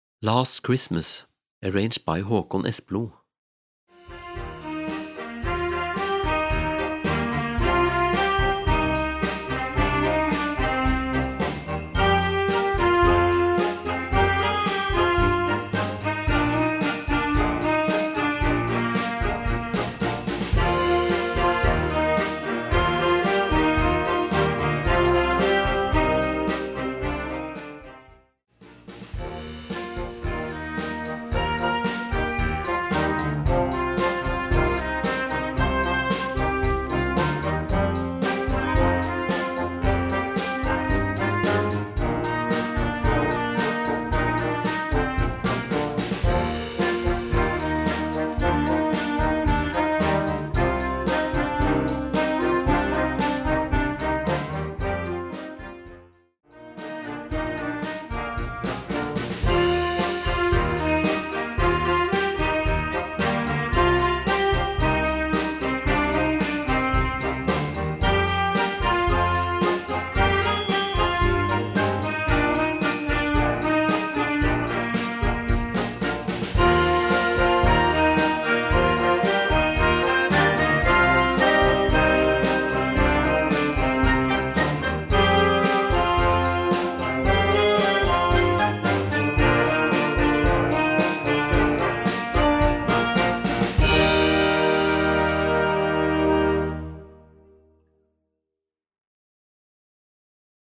Gattung: Weihnachtswerk Jugend
Besetzung: Blasorchester